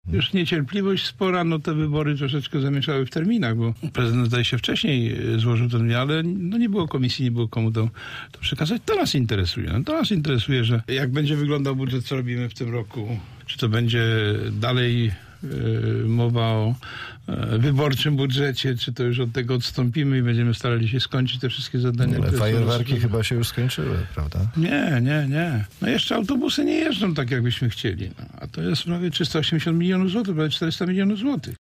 Pytaliśmy o to dzisiaj naszego gościa Andrzeja Bocheńskiego z klubu radnych Bezpartyjni Samorządowcy: